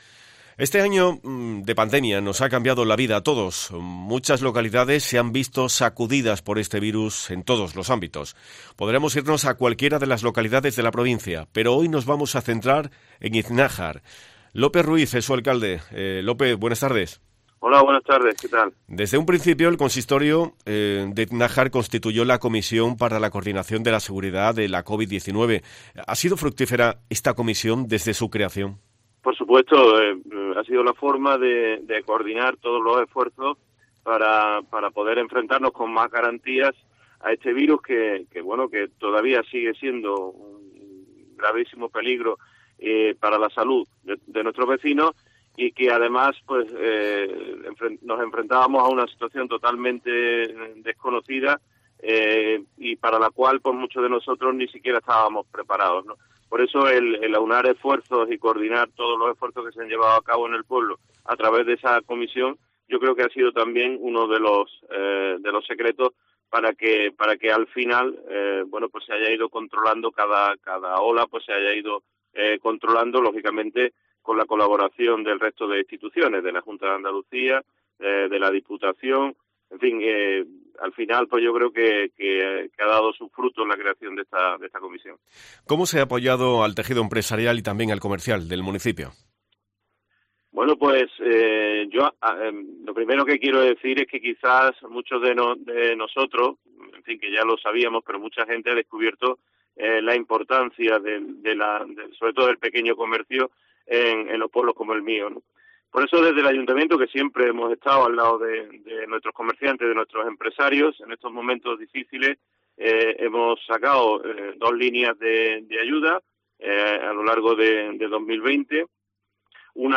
Lope Ruiz Lopez, alcalde de Iznájar